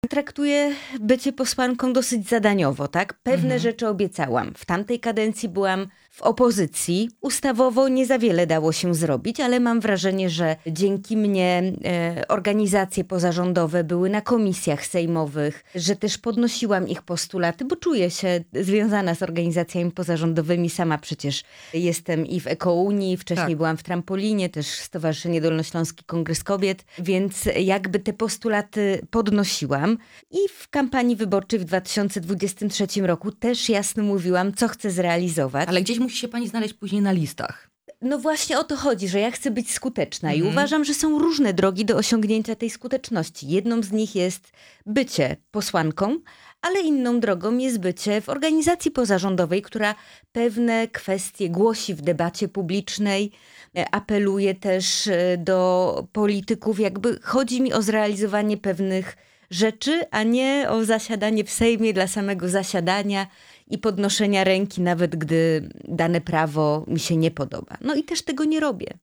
Posłanka na Sejm Małgorzata Tracz byłą naszym „Porannym Gościem”. Rozmawiamy o zakazie hodowli zwierząt na futro, ustawie łańcuchowej, systemie kaucyjnym oraz jej odejściu z partii Zieloni i założeniu Stowarzyszenia „Naprzód”.